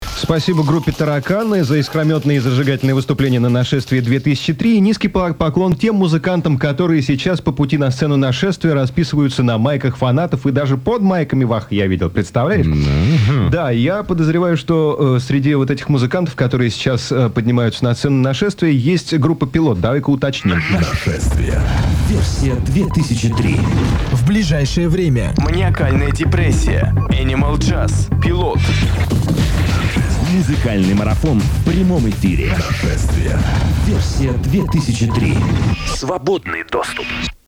Разговор в студии